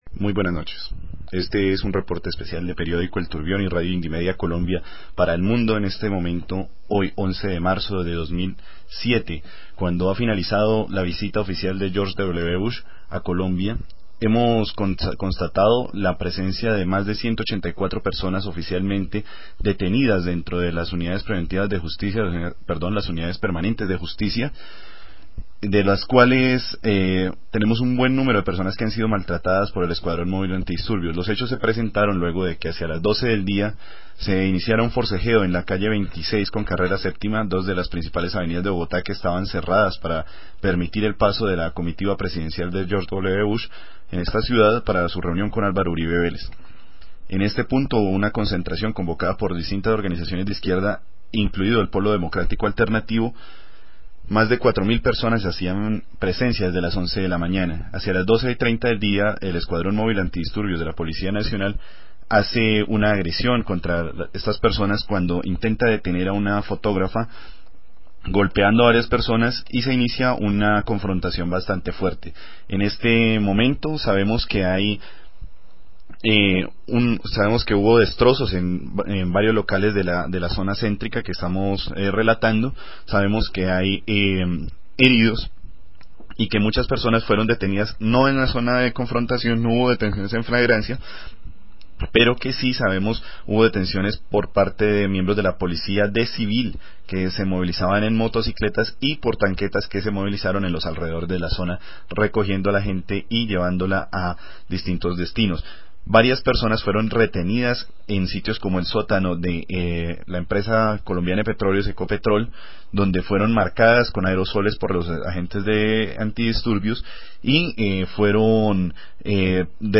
Entrevista miembro RedHer partecipante en la marcha